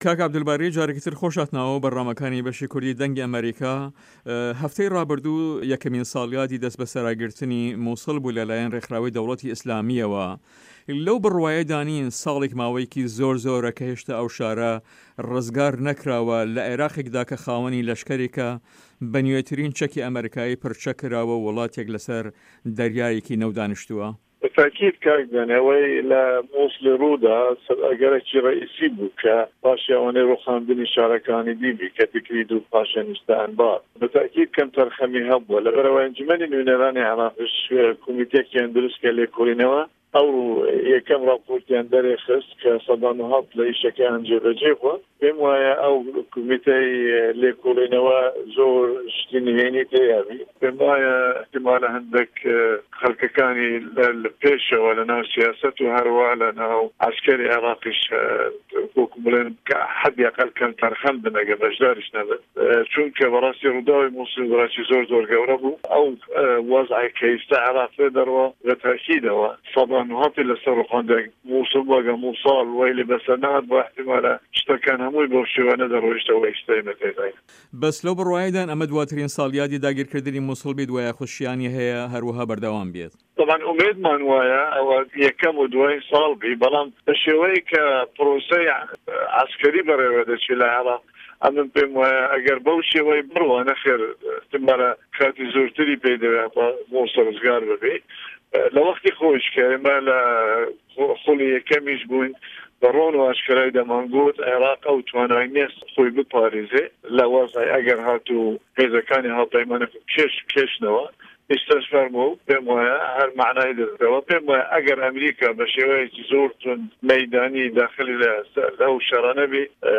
عه‌بدولباری زیباری ئه‌ندام په‌رله‌مانی عراق و جێگری سه‌رۆکی ئه‌نجومه‌نی په‌یوه‌ندیه‌کانی په‌رله‌مان له‌ هه‌ڤپه‌یڤینێکدا له‌گه‌ڵ به‌شی کوردی ده‌نگی ئه‌مه‌ریکا ده‌ڵێت " بێگومان ئه‌وه‌ی له‌ موسڵ رووی دا ئه‌گه‌رێکی سه‌ره‌کی بوو و پاشان رووخانی شاره‌کانی تر تکریت و پاشان ئه‌نبار، بێگومان که‌مته‌رخه‌می هه‌بوه‌، هه‌ر له‌به‌ر ئه‌وه‌ ئه‌نجومه‌نی نوێنه‌رانی عێراق کۆمیته‌یه‌کیان بۆ لێکۆڵینه‌وه‌ درووستکرد که‌ ئه‌مرۆ یه‌کم راپۆرتیان دره‌خست که‌ %90 له‌ کاره‌که‌یان جێ به‌ جێ بوه‌ و پێیم وایه‌ راپۆرتی ئه‌و کۆمیته‌ زۆر شتی نهێنی تێدا بێت که‌ شیانی هه‌یه‌ هه‌ندێ له‌و که‌سانه‌ی له‌ پۆستی باڵادا له‌ بواری سیاسی و له‌شکه‌ری له‌ کاره‌کانیاندا که‌م ته‌رخه‌م بن.